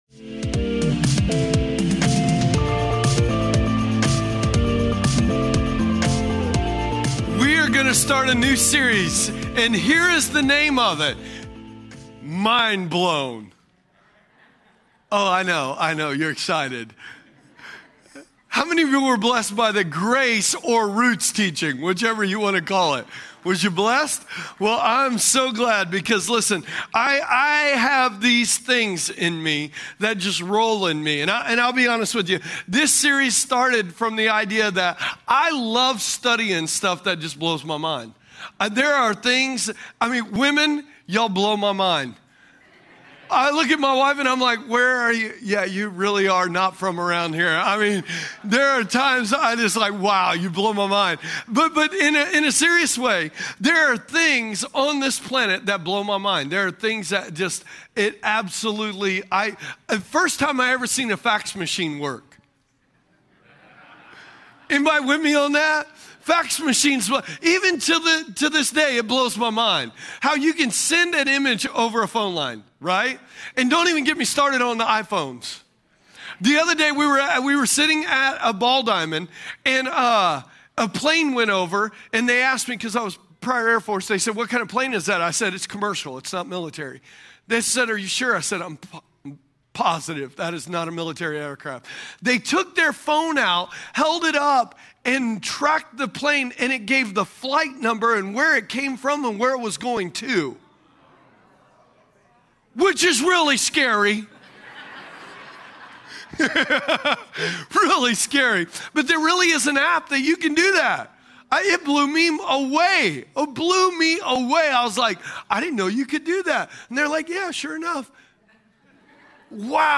2016 Category: Sermons ...